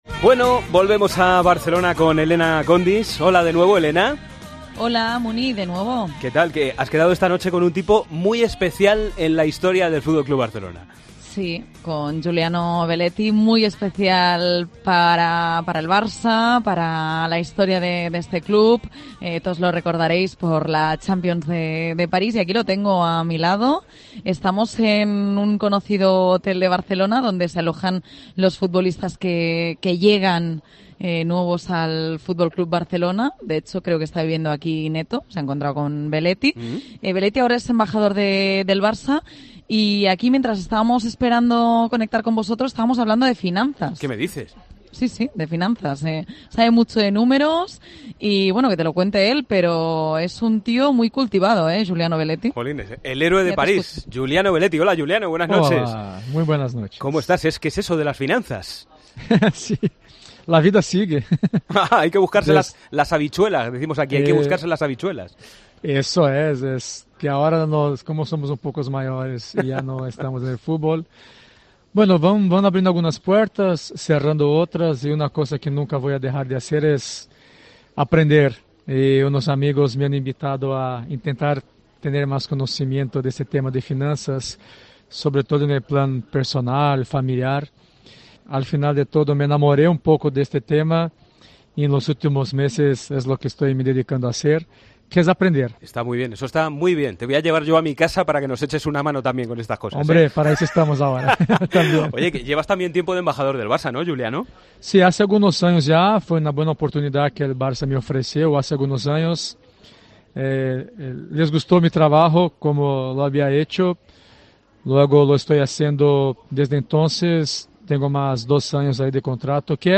El ex jugador del FC Barcelona y héroe en la final de la Champions de 2006, Juliano Belletti pasó este miércoles por los micrófonos de ' El Partidazo de COPE ' para analizar el futuro de Neymar, el de Coutinho o el de las nuevas estrellas del Madrid Vinicius Júnior y Rodrygo Goes.